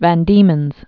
(văn dēmənz, vän)